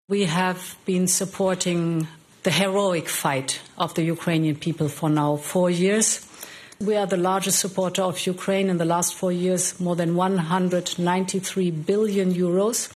European commission president Ursula Von Der Leyen insists they’ll continue to back Ukraine: